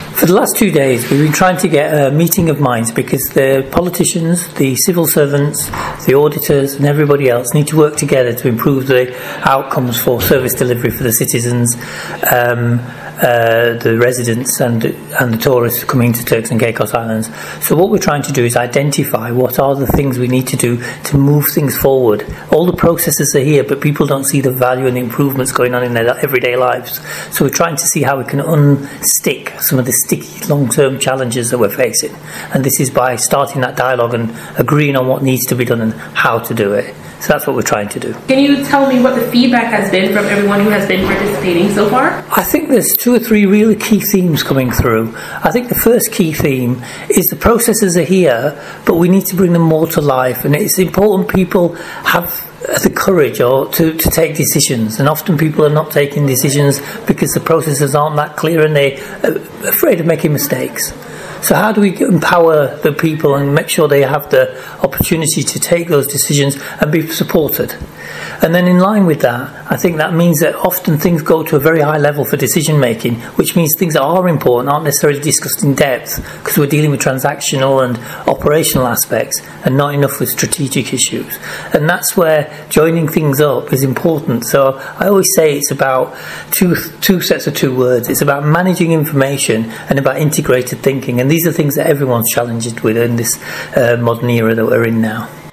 We also heard from Auditor General Mr. Khalid Hamid on his thoughts on the workshop and its benefits.